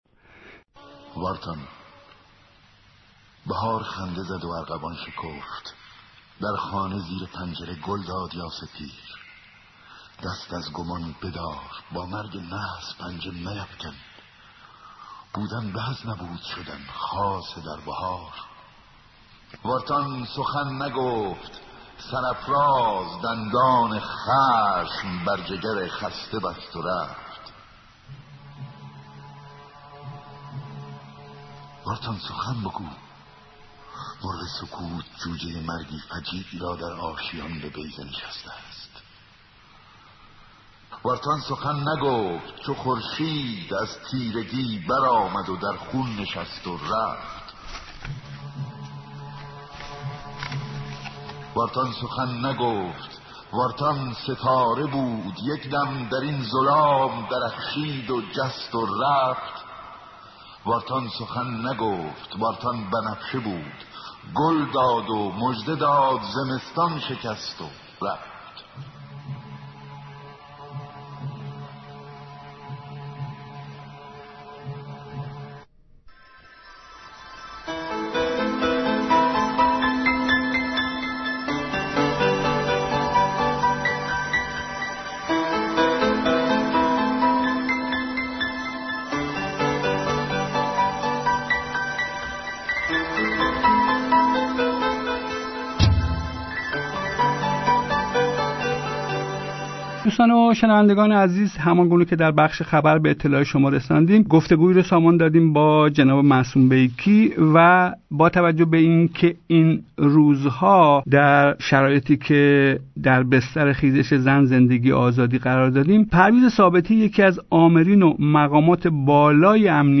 آزادی اعضا کانون نویسندگان ایران مدیون گسترش مبارزات زن،زندگی، آزادی است گفتگوی رادیو پیام آزادی